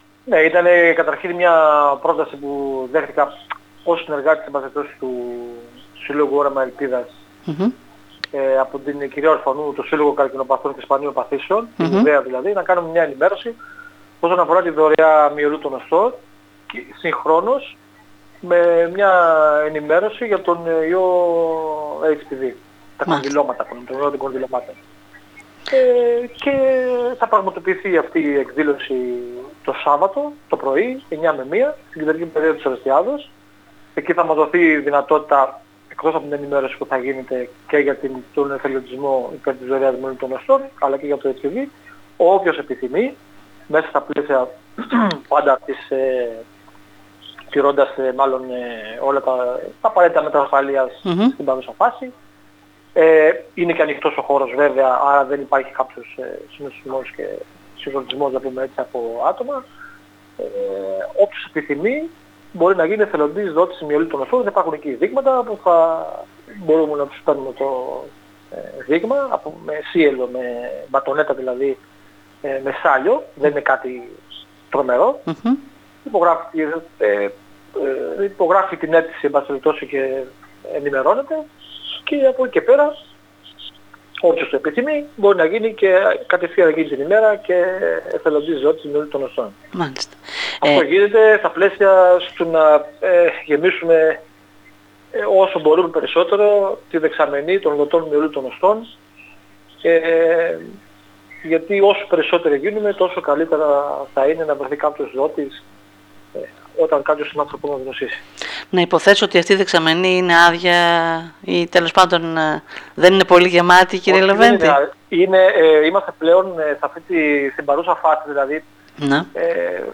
μιλώντας στην ΕΡΤ Ορεστιάδας